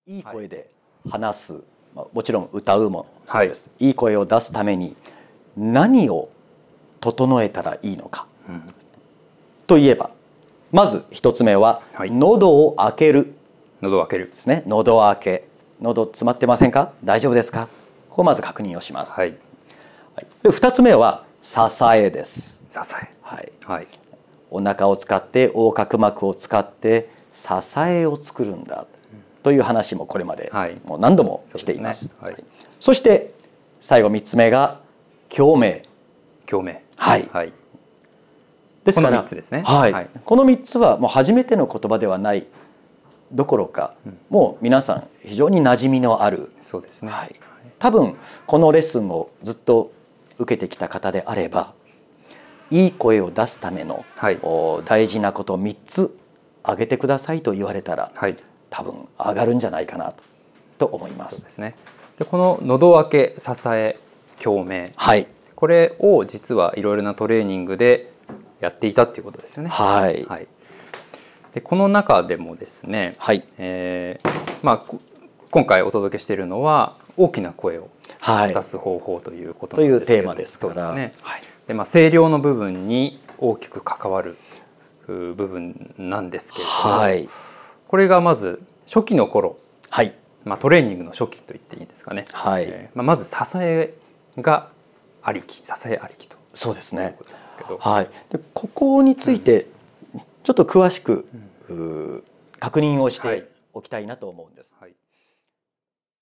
今回のトレーニングでもイメージしやすい言葉を使いながら実際に共鳴している声を聴いてもらいます。ぜひこの音声レッスンを聴きながら、実践していってください。